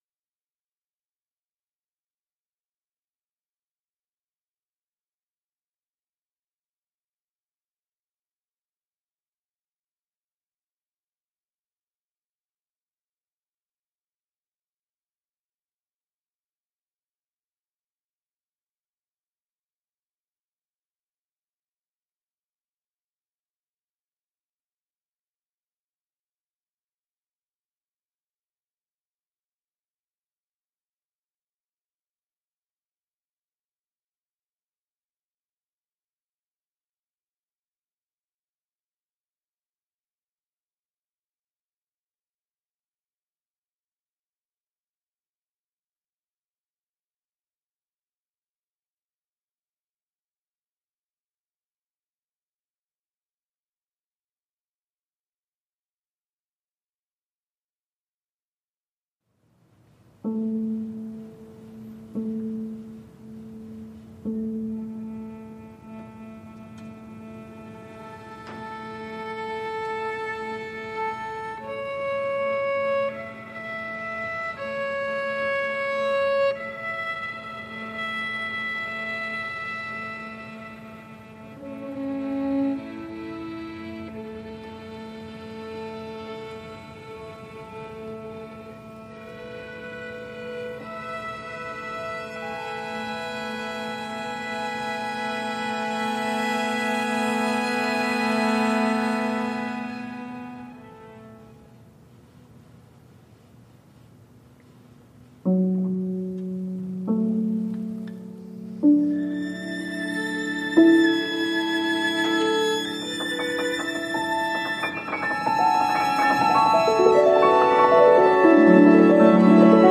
Sanctuary-November-22-audio.mp3